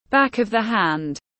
Back of the hand /bæk əv ðiː hænd/